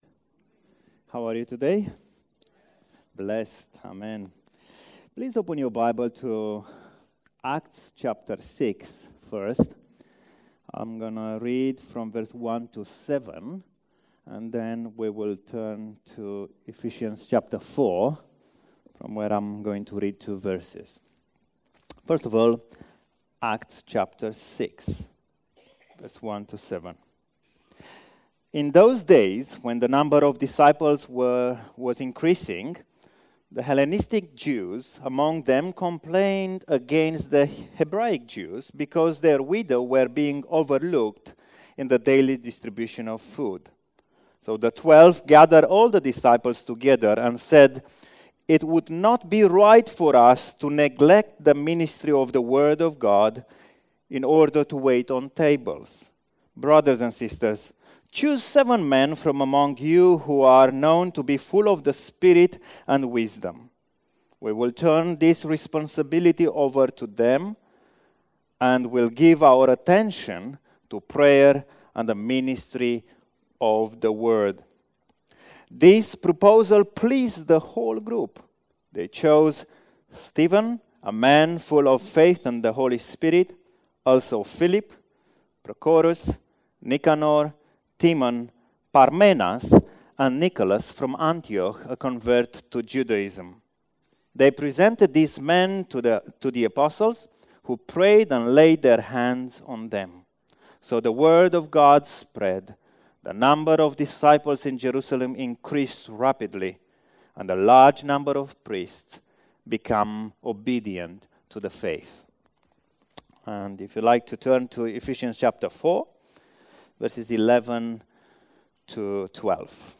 Sermon-12th-Jan.mp3